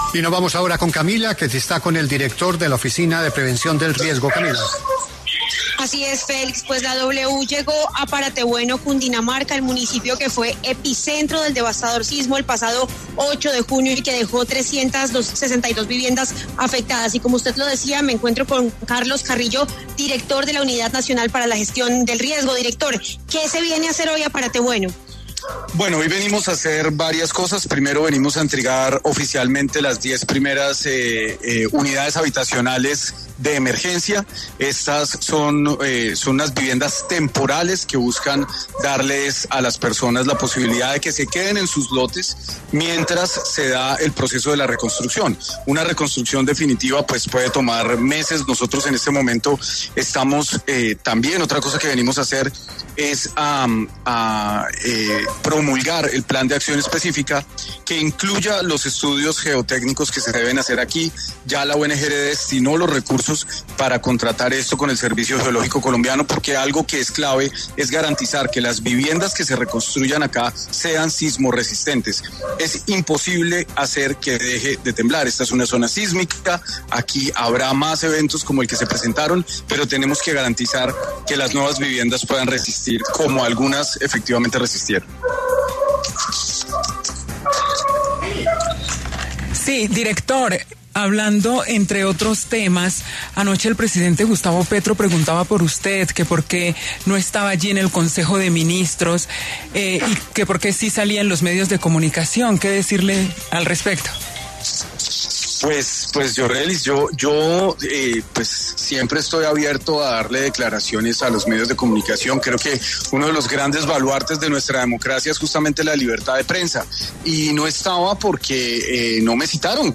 El director de la UNGRD, Carlos Carrillo, habló en La W tras la entrega de 10 casas temporales en el municipio que fue epicentro del sismo del pasado 8 de junio.